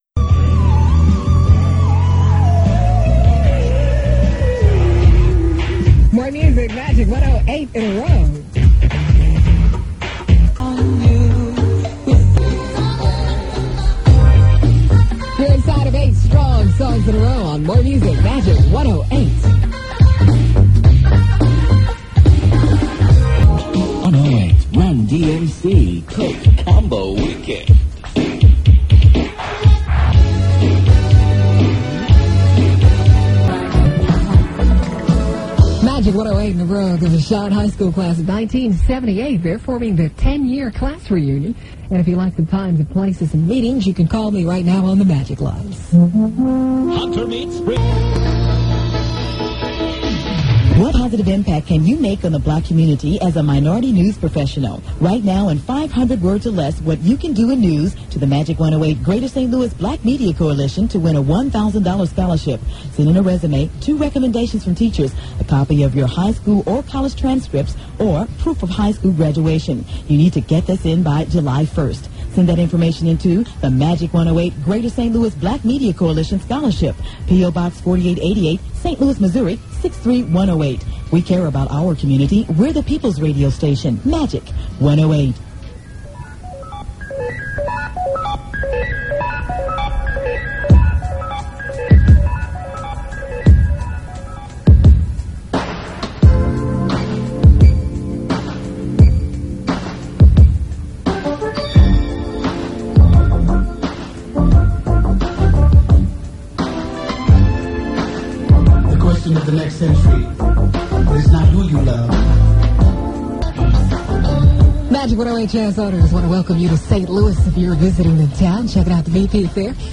Original Format aircheck